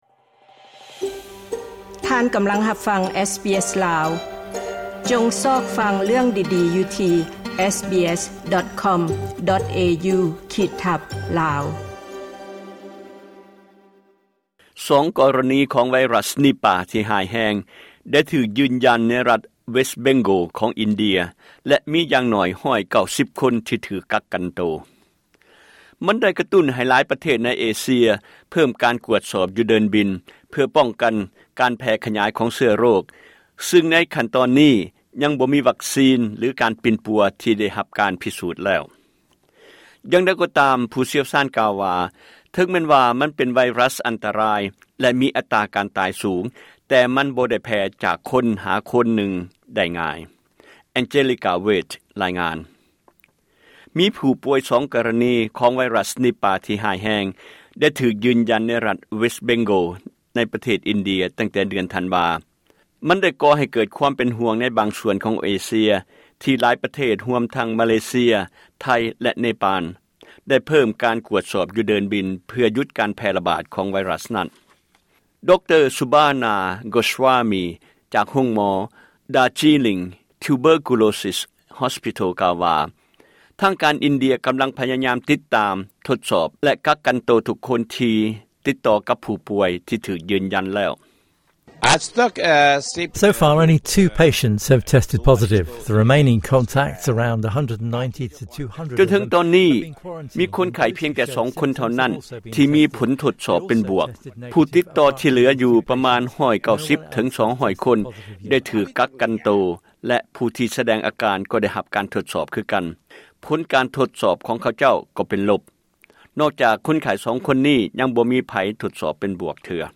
ລາຍງານ.